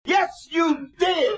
Tags: ASA Sports announcing